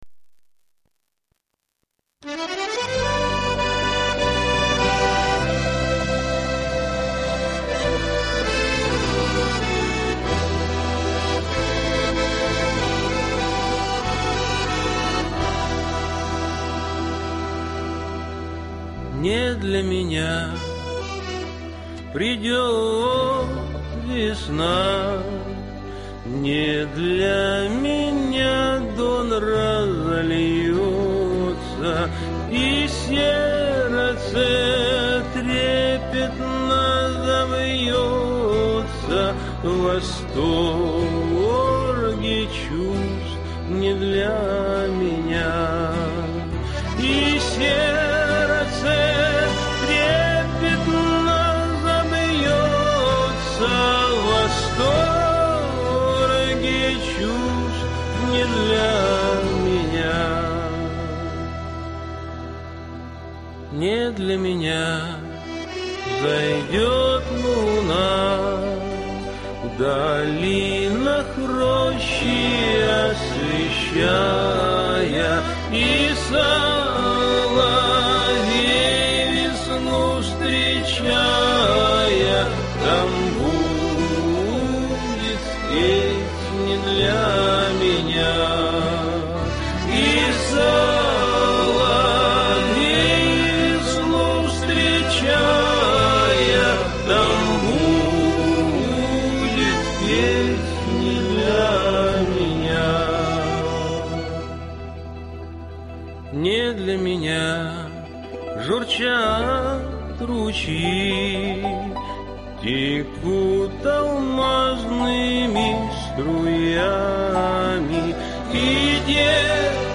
старинную казачью песню - "Не для меня".
Манера пения : для себя, не на экспорт.